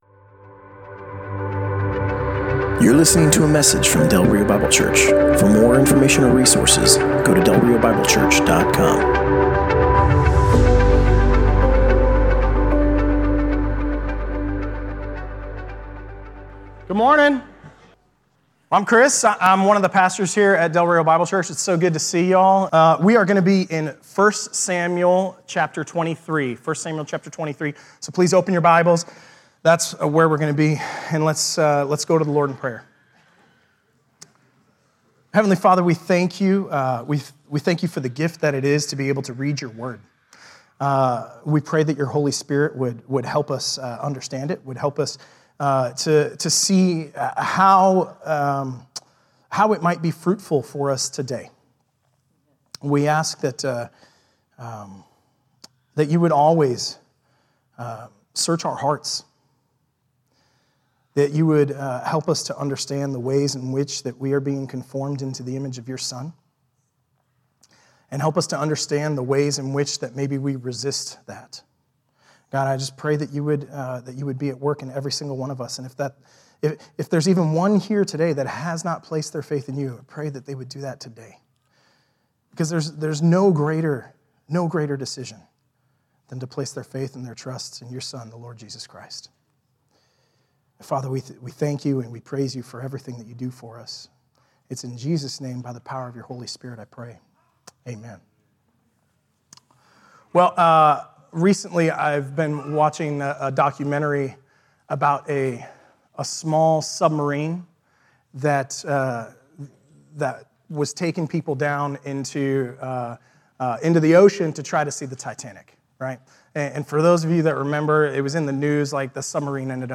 Passage: 1 Samuel 23: 1-29 Service Type: Sunday Morning